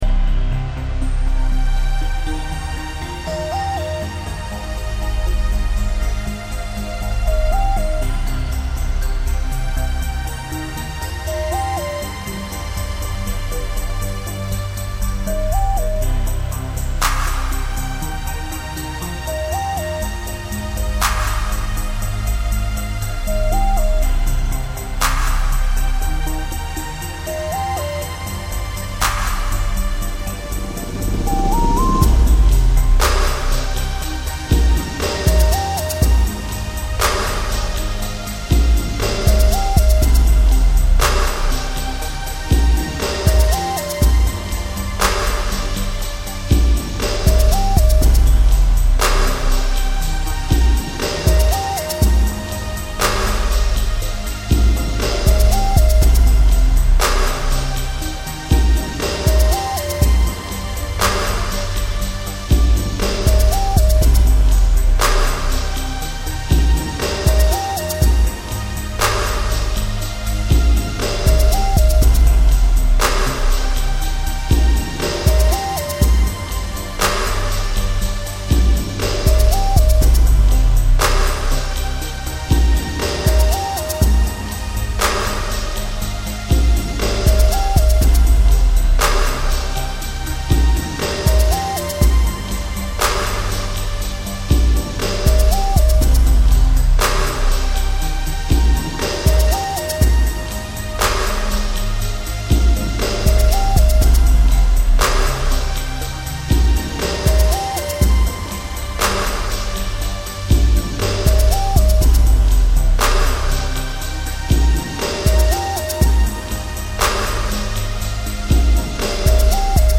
Hard-Trance, Trance